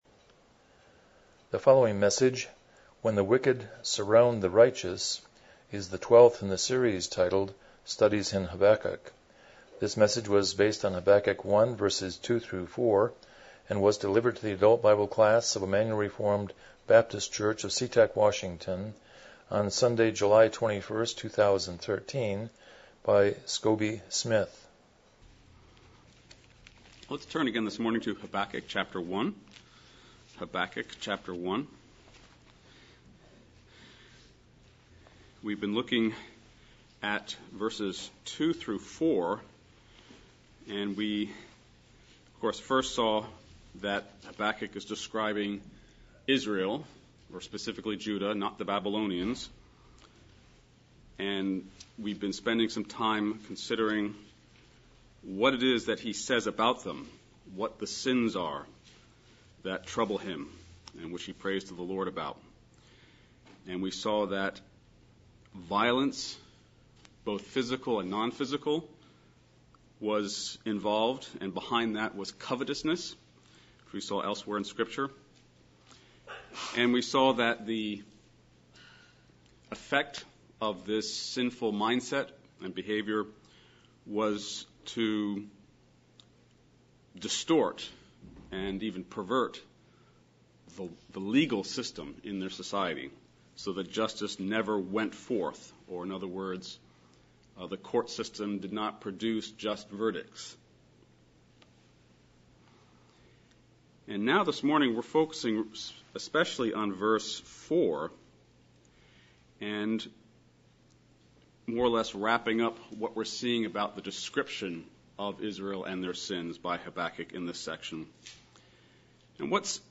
Passage: Habakkuk 1:2-4 Service Type: Sunday School